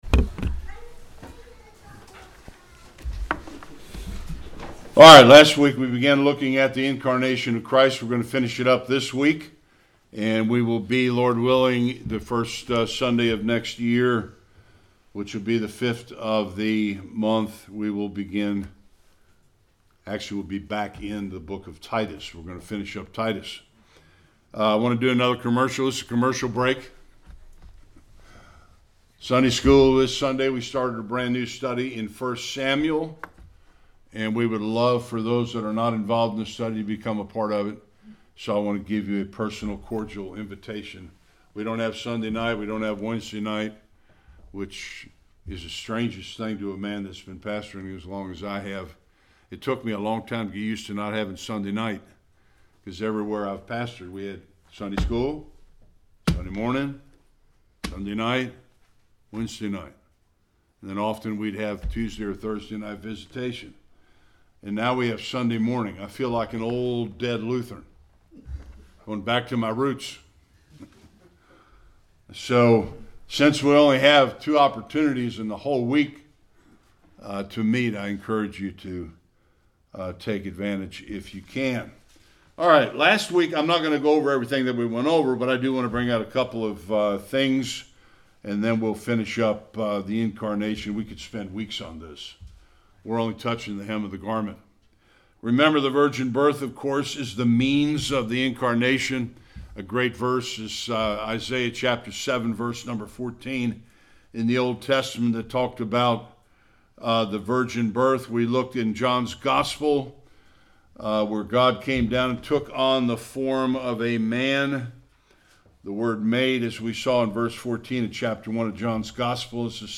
Various Passages Service Type: Sunday Worship The humanity of Jesus Christ is not an opinion